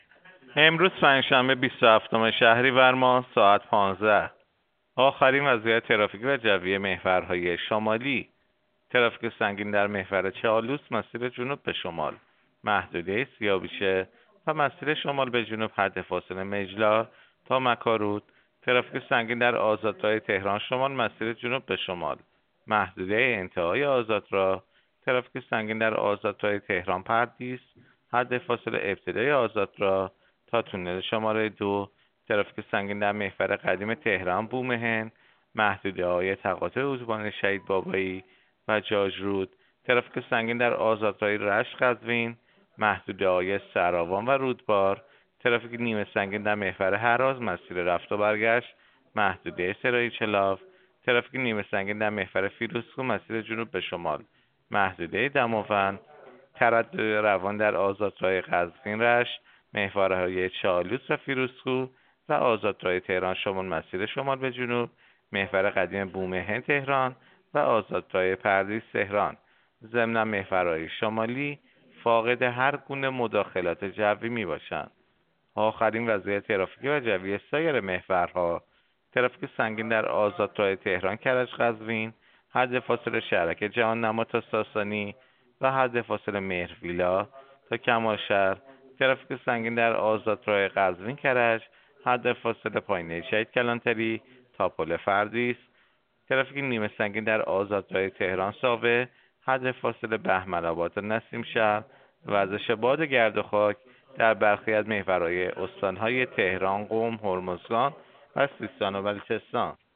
گزارش رادیو اینترنتی از آخرین وضعیت ترافیکی جاده‌ها ساعت ۱۵ بیست و هفتم شهریور؛